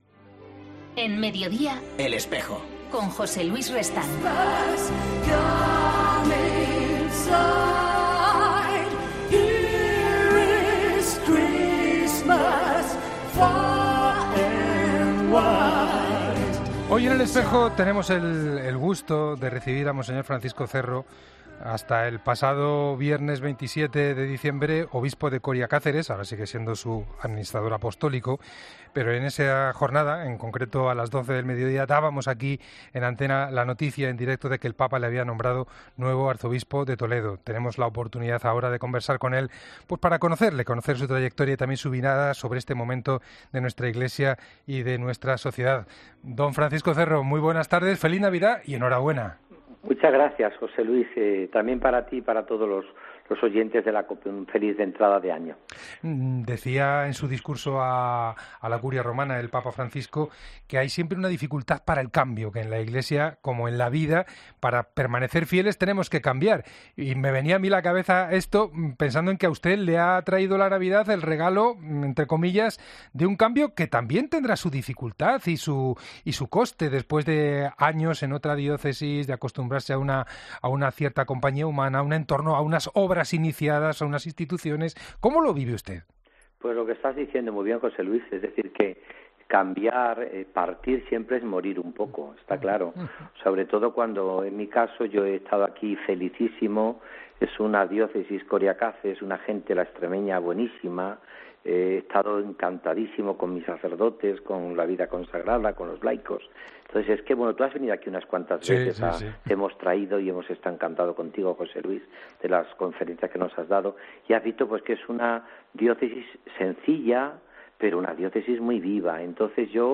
Mons. Francisco Cerro, actual obispo de Coria-Cáceres, fue nombrado el pasado viernes Arzobispo de Toledo. Hoy hablamos con él en 'El Espejo'.